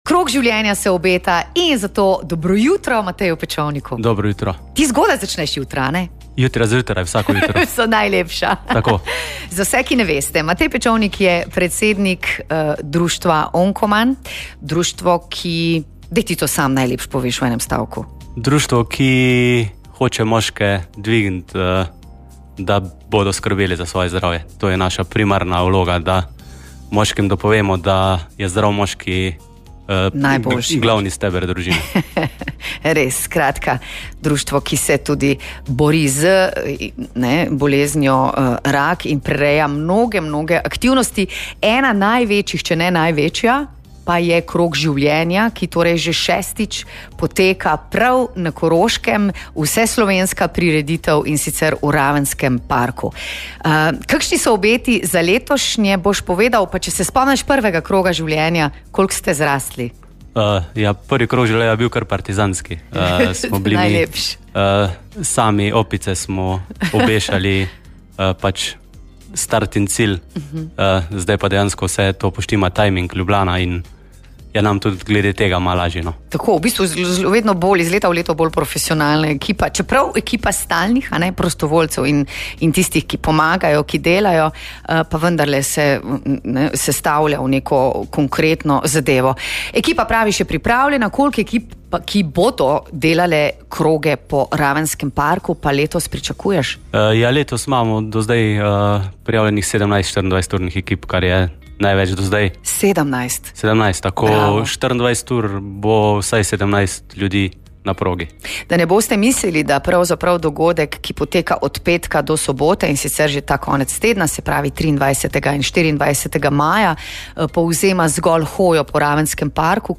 Z njim se je pogovarjala